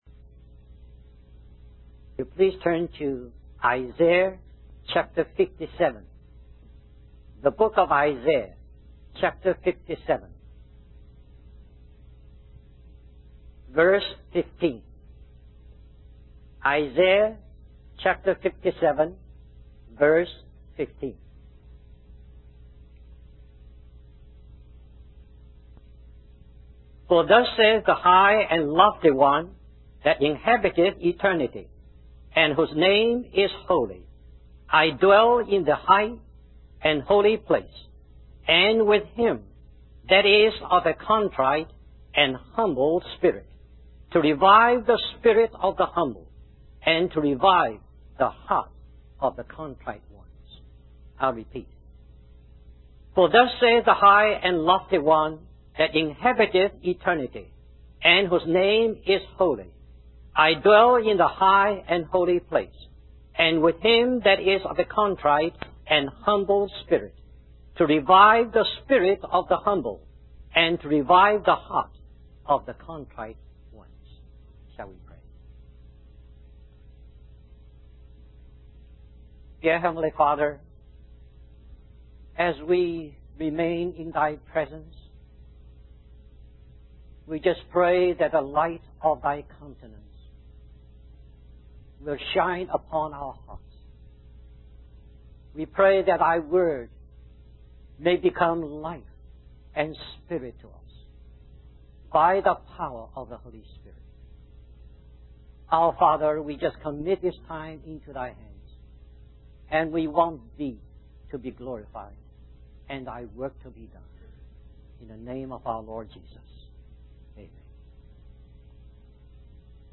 In this sermon, the speaker emphasizes the importance of the condition of our spirit in our spiritual life.